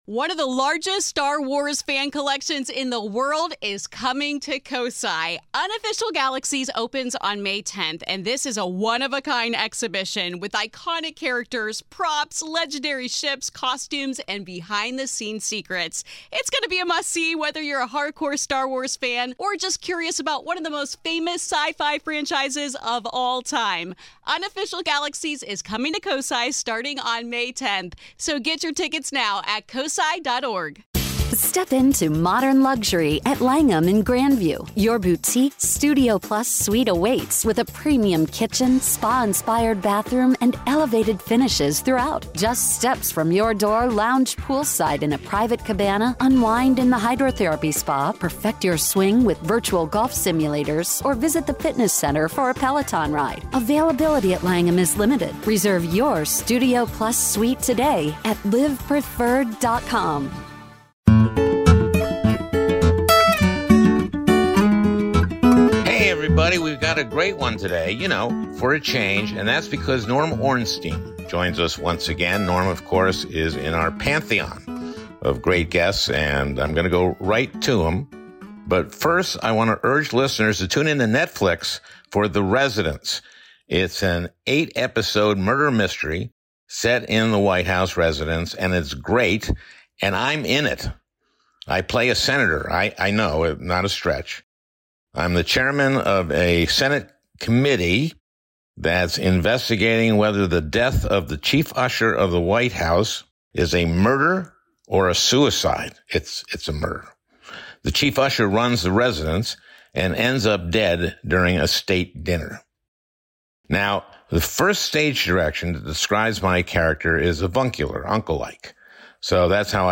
It's been another chaotic week in Donald Trump's America, and we're thrilled to have our good friend, Norm Ornstein, back on the podcast. We talk about Trump's Executive Order TO dismantle the Department of Education and what that could mean to communities across the country. We also break down the divisions in the Democratic Party and why Leader Chuck Schumer didn't help the situation by caving to Donald Trump. Plus, what is the future of Ukraine and America's standing in the world as we abandon our allies?